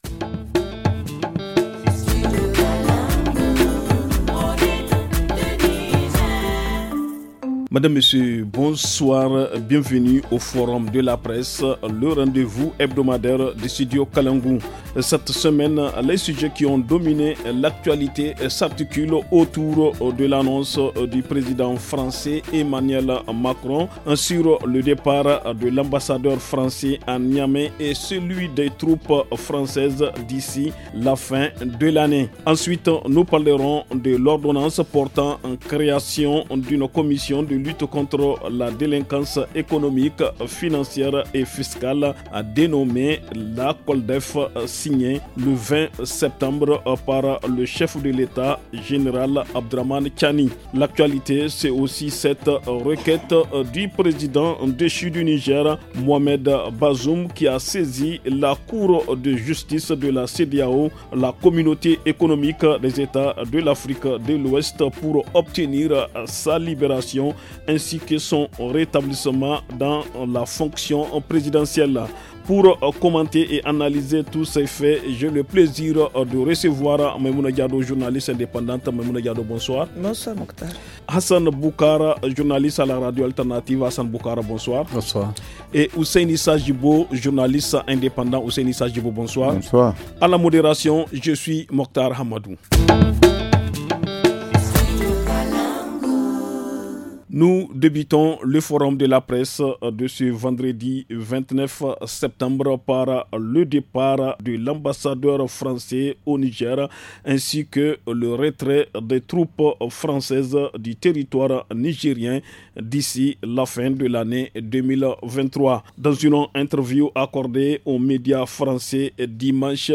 journaliste indépendante .
journaliste indépendant.